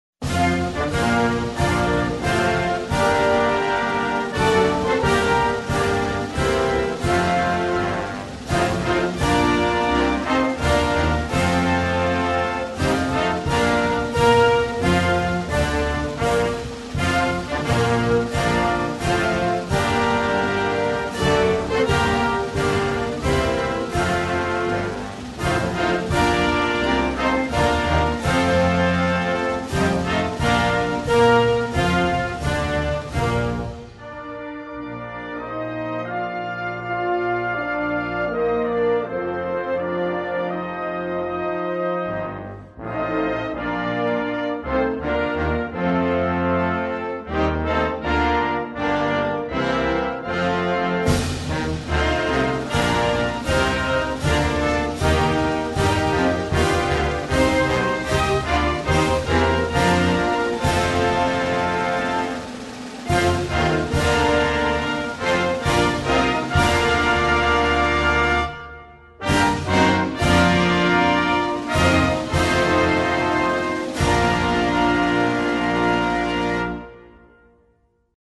Hymne USA
Hymne-National-USA.mp3